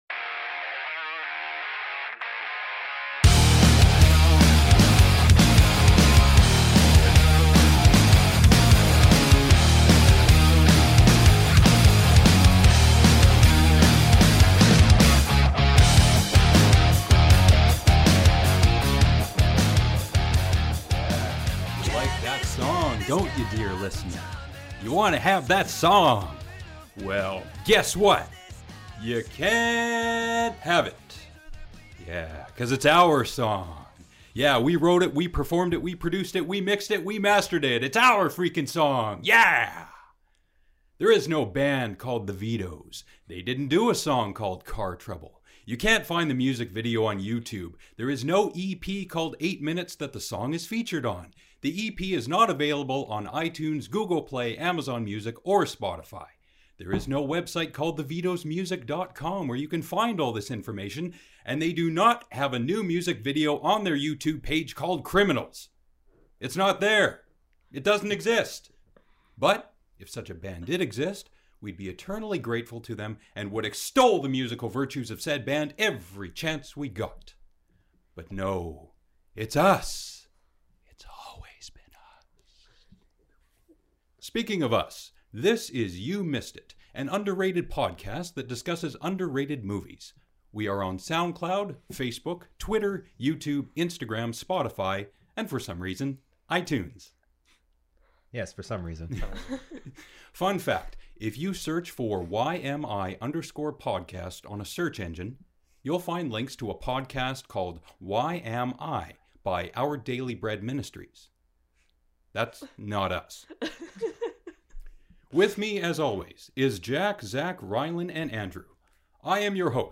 You Missed it?: A weekly podcast in which five cinephiles watch lesser-known films that were overlooked upon their initial release. Each episode, one of the hosts selects a movie to watch that they feel has not received the credit it deserves; afterwards everyone discusses and offers their opinions and their analyses to determine whether or not the movie is indeed underrated.